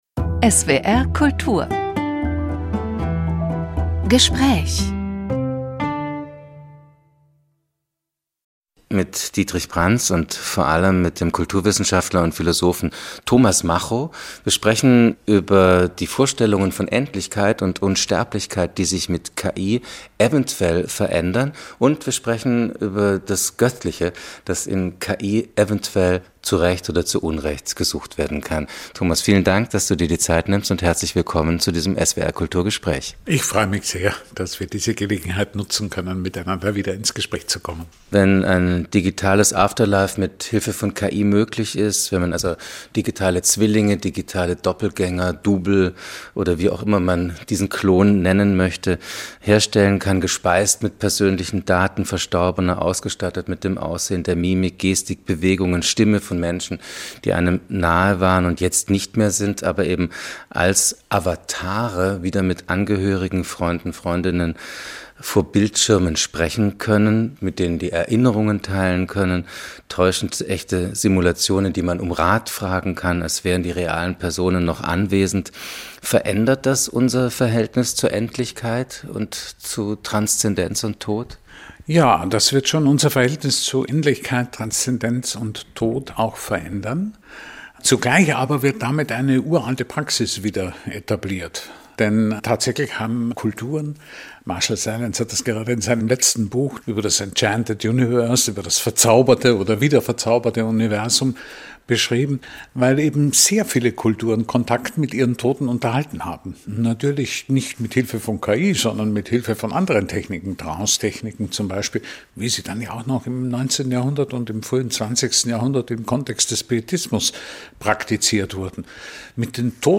Gespräch | KI und Kreativität (4/6)